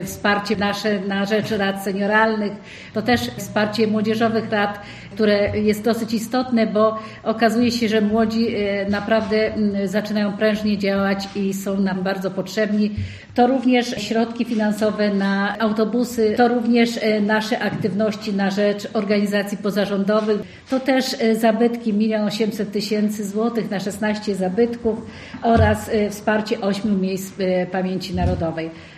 O programach wsparcia mówiła podczas konferencji w Ostrołęce członkini zarządu województwa, Elżbieta Lanc: